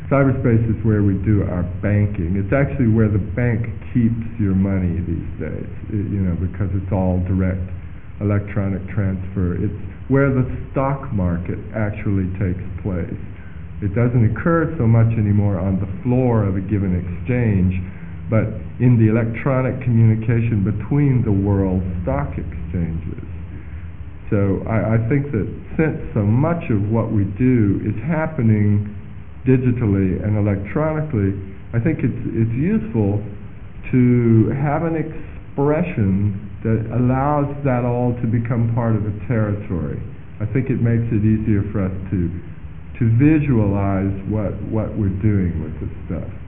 46. An Interview With William Gibson
Listen to William Gibson's definition of "Cyberspace". Reasonable sound quality (361 k)